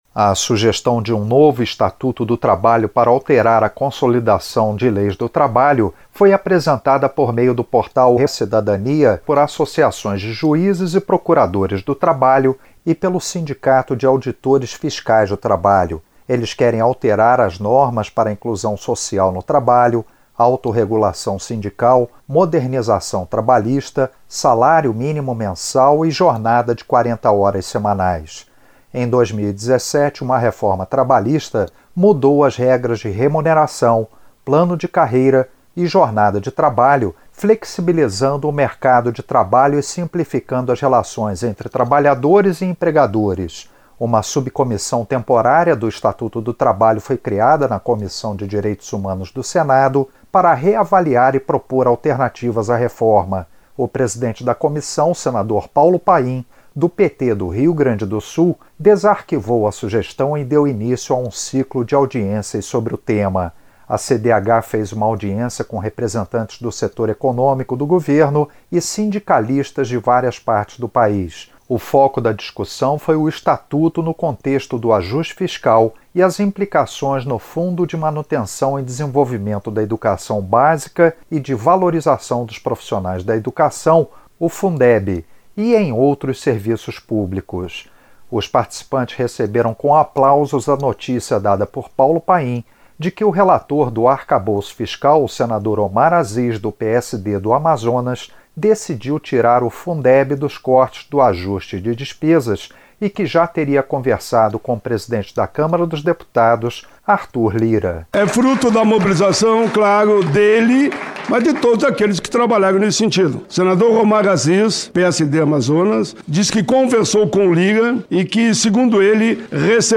Representante do Ministério da Fazenda afirma no Senado que o arcabouço fiscal garante verba para a educação básica e outras transferências constitucionais. Ela falou na Comissão de Direitos Humanos do Senado (CDH) em audiência sobre a proposta de novo estatuto trabalhista. Nesta quinta-feira (15) houve a quinta audiência com a participação de representantes do setor econômico do governo e sindicalistas de várias partes do país.